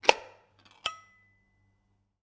Can Opening Sound
household